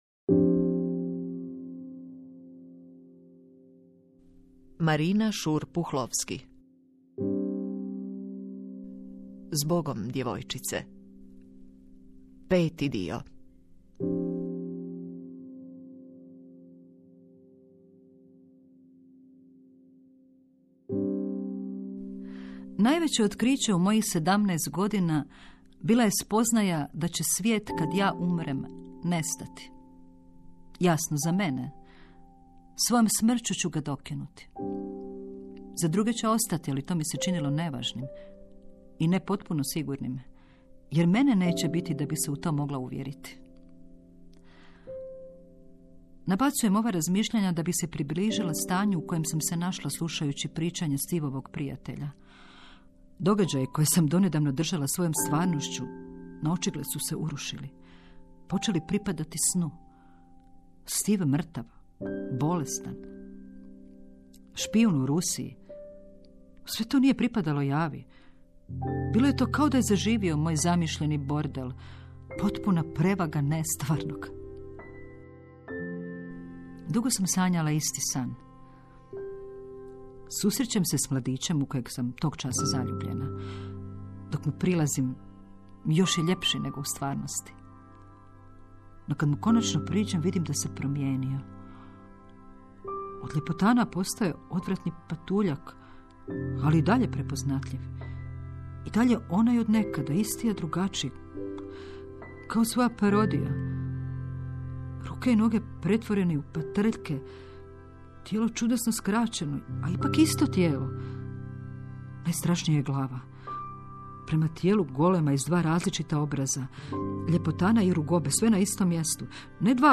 Suvremena prozna produkcija hrvatskih autora narativno-igrane dramaturgije, u formi serijala.